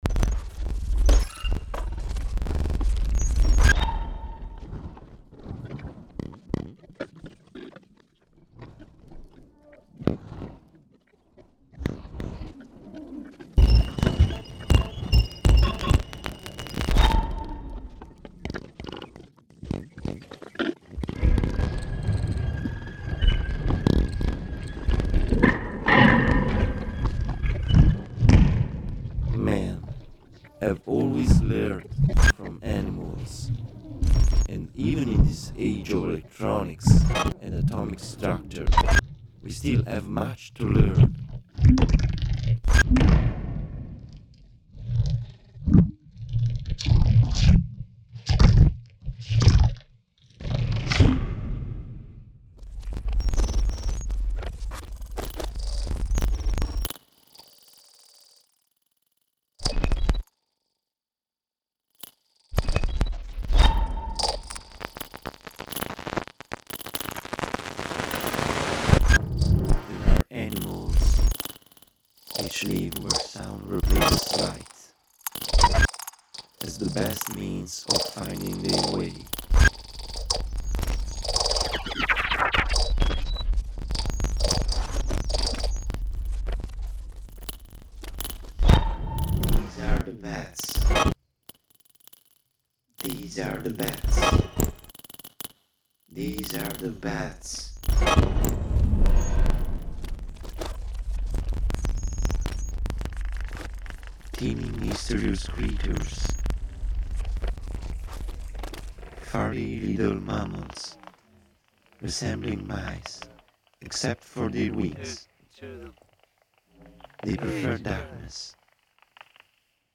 [è raccomandato l’ascolto in cuffia]